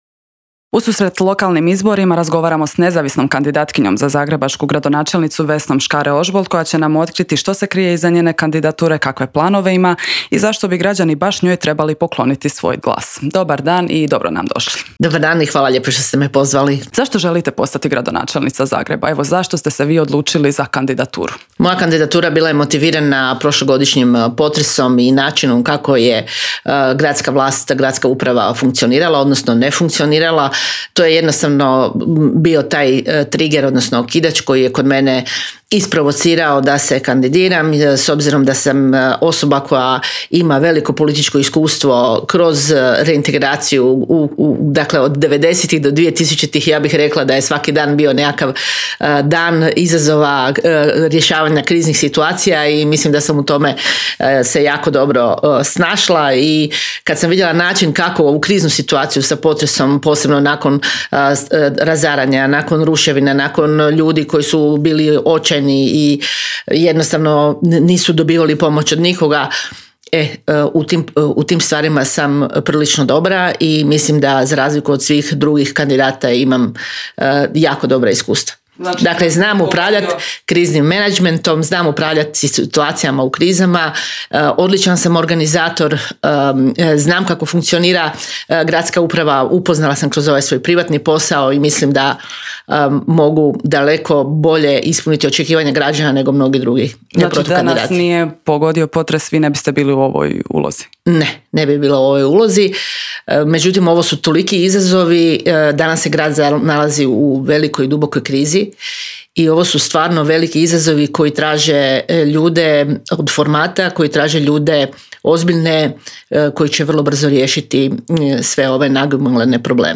ZAGREB - Nezavisna kandidatkinja za zagrebačku gradonačelnicu Vesna Škare-Ožbolt u razgovoru za Media servis predstavila je svoj plan i program za Gra...